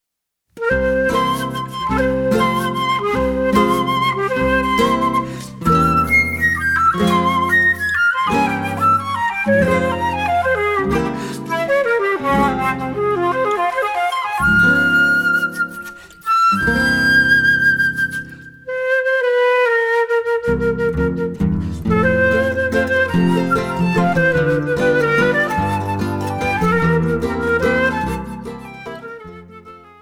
flauta